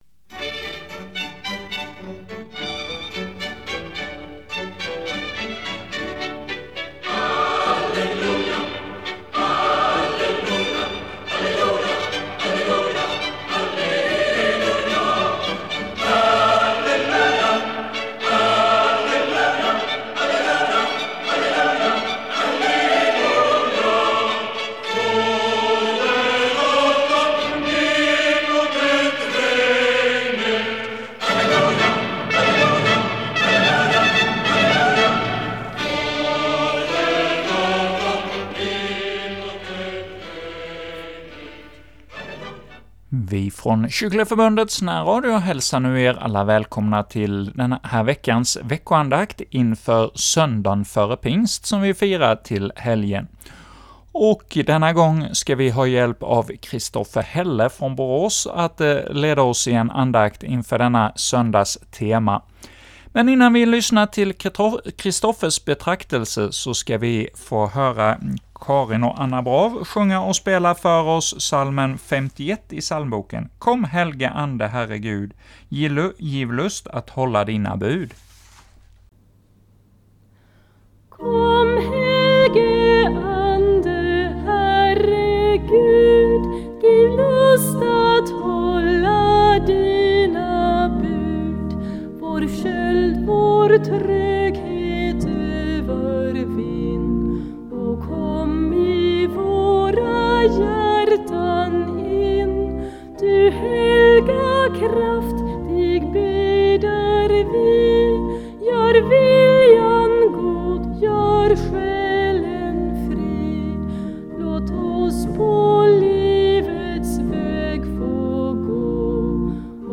andakt